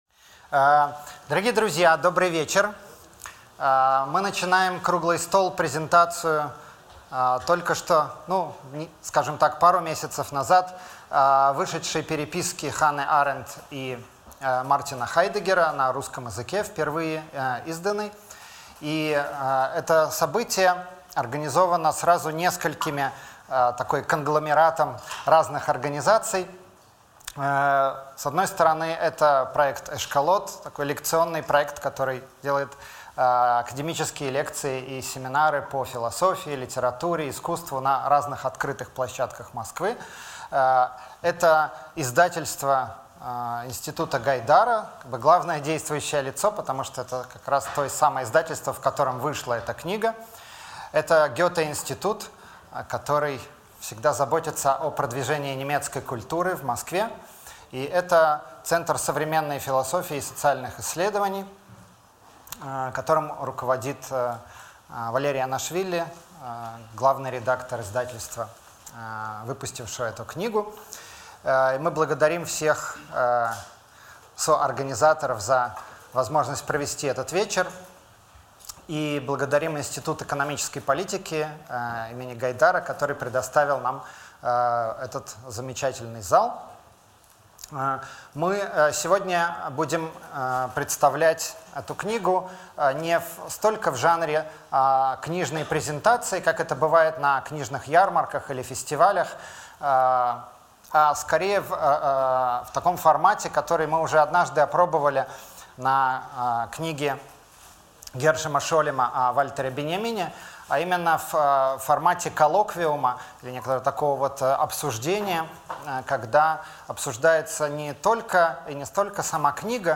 Аудиокнига Арендт/Хайдеггер | Библиотека аудиокниг